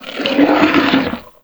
MONSTER_Noise_01_mono.wav